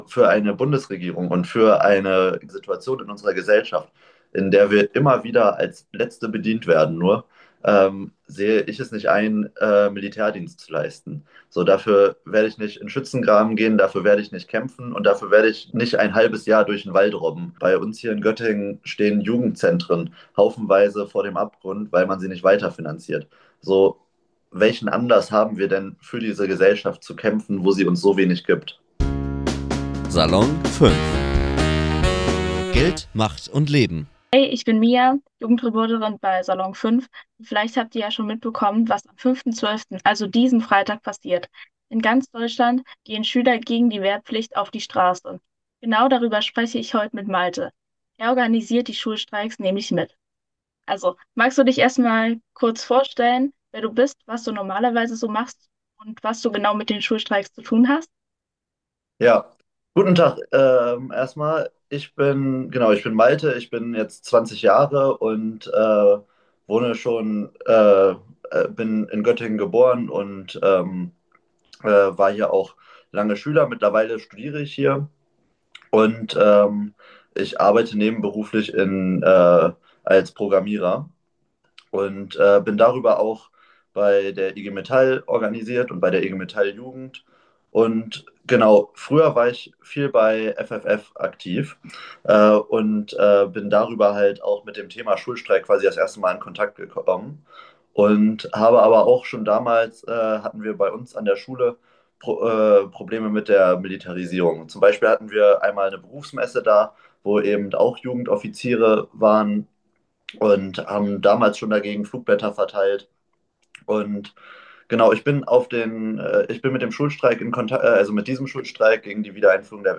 Diese für Audio optimierte Kompaktfassung des täglichen Spotlight-Newsletters ist von einer KI-Stimme eingelesen und von Redakteuren erstellt und geprüft.